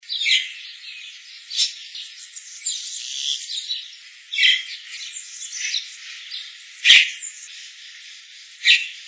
Boyero Cacique (Cacicus haemorrhous)
Nombre en inglés: Red-rumped Cacique
Fase de la vida: Adulto
Localidad o área protegida: Puerto Iguazú
Condición: Silvestre
Certeza: Vocalización Grabada
boyero-cacique.mp3